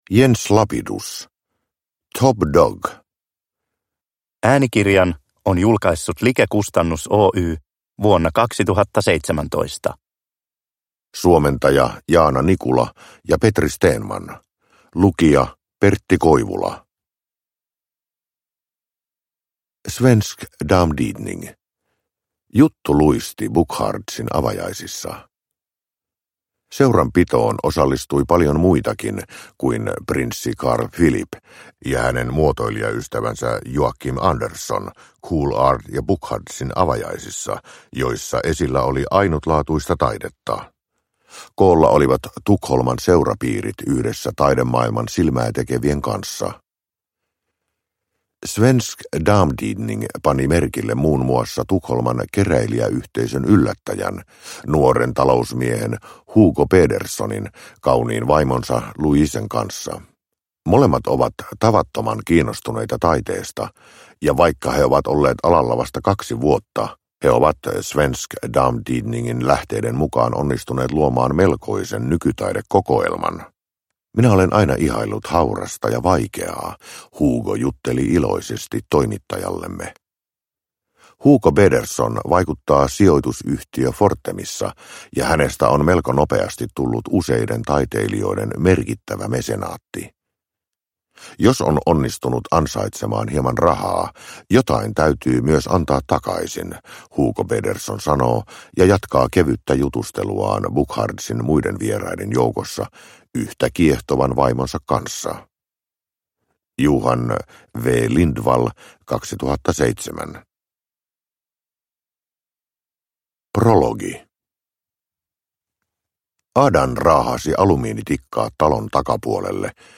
Top dog – Ljudbok – Laddas ner